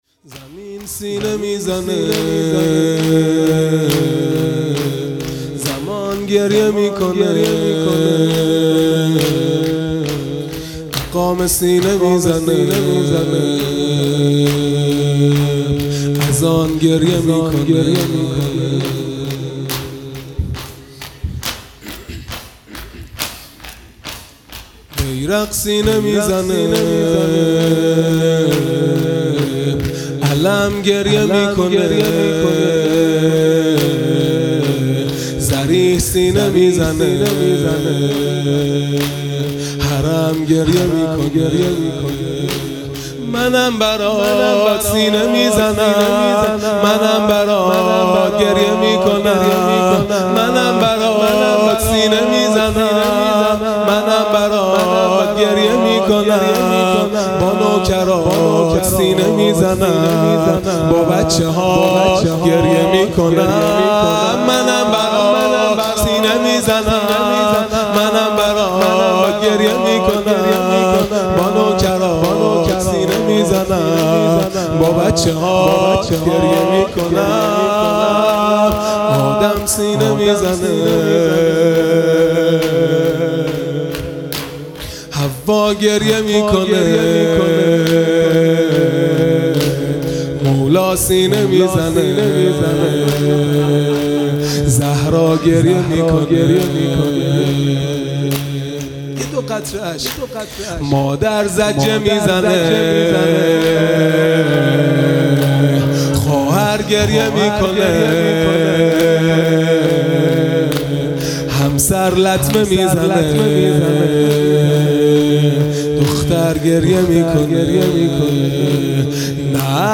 خیمه گاه - هیئت بچه های فاطمه (س) - شور | زمین سینه میزنه
عزاداری دهه آخر صفر المظفر (شب اول)